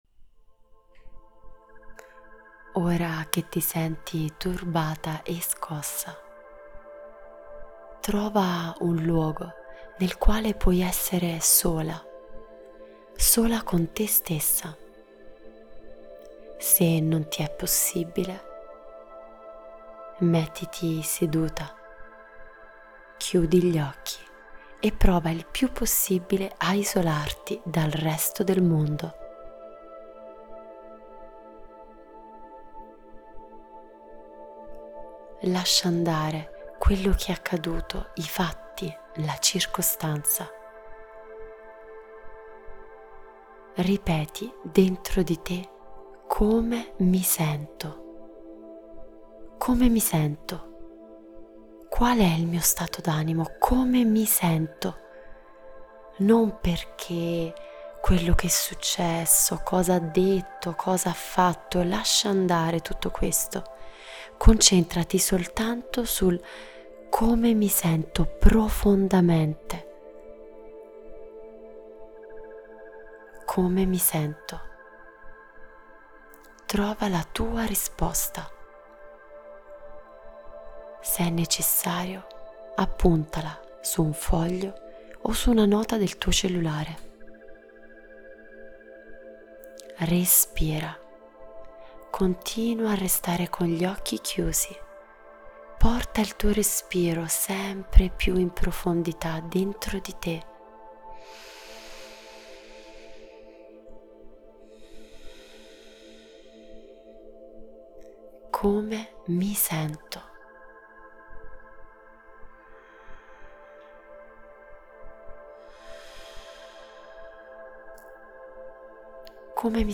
MEDITAZIONE-PER-SCOPRIRE-LA-TUA-FERITA-PRIMARIA.mp3